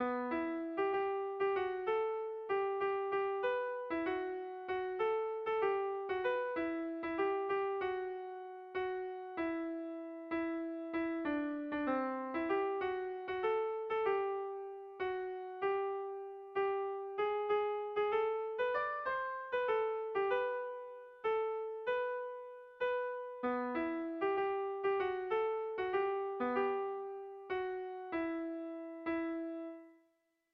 Erlijiozkoa
Hamarreko txikia (hg) / Bost puntuko txikia (ip)
ABDEF